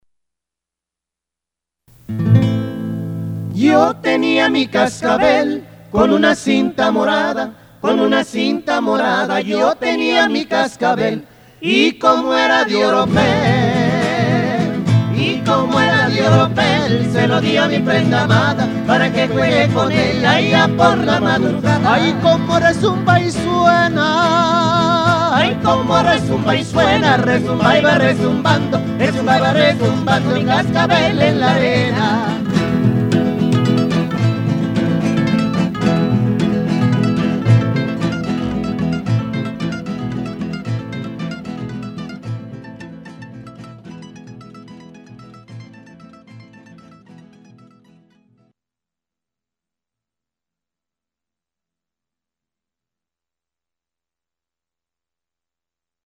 Mariachi Trio for Hire...
(Mariachi)